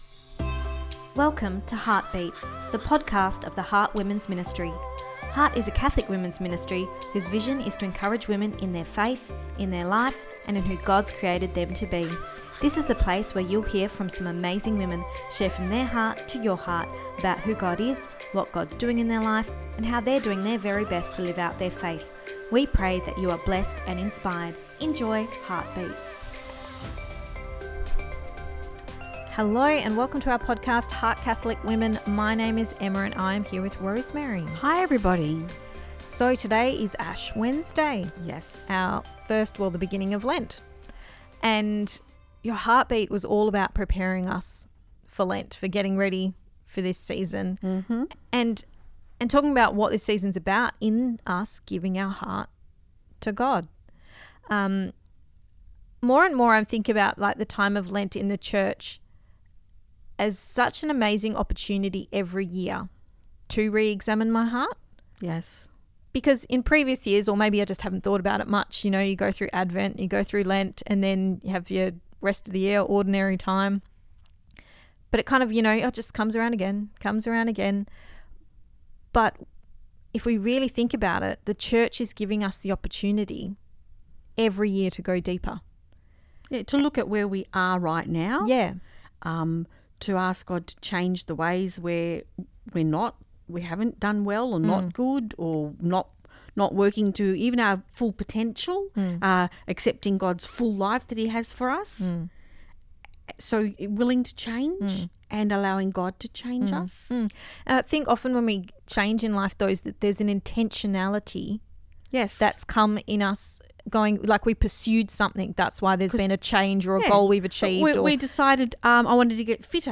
Ep236 Pt2 (Our Chat) – Lent 2025: I Give You My Heart Lord